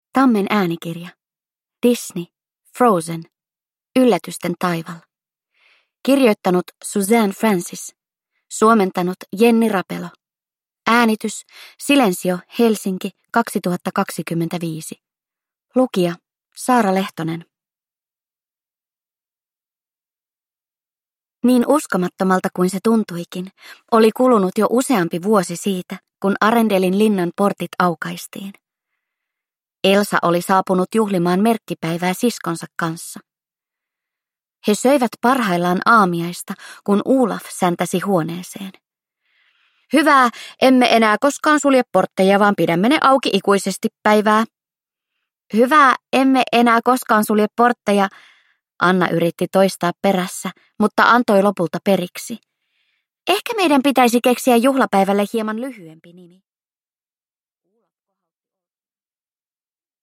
Disney. Frozen. Yllätysten taival (ljudbok) av Disney